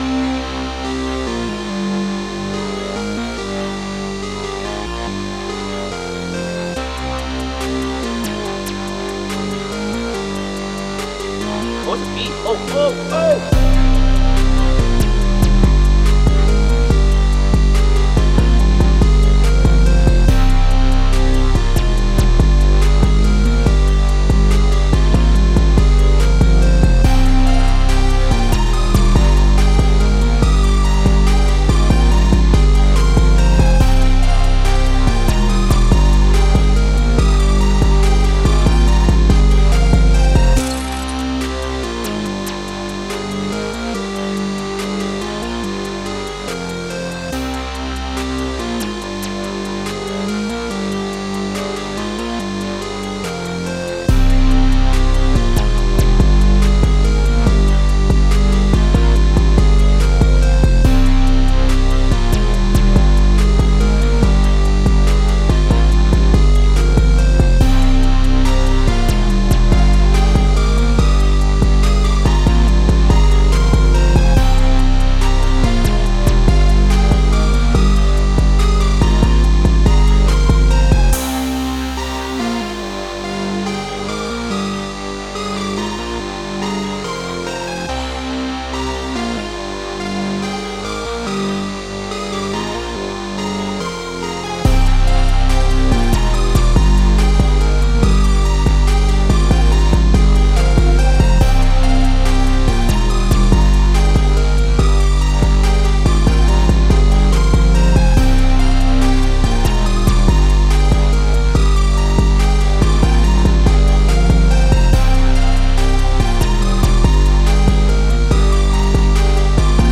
Time – (2:15)　bpm.142